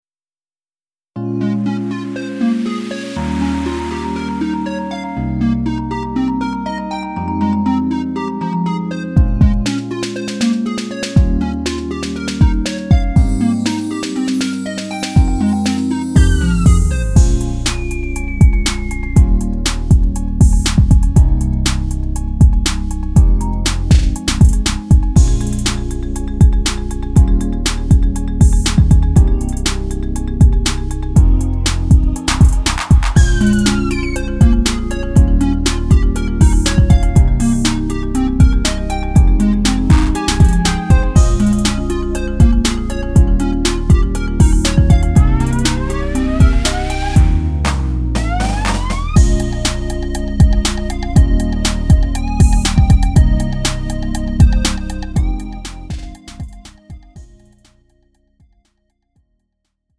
음정 -1키 3:25
장르 가요 구분 Lite MR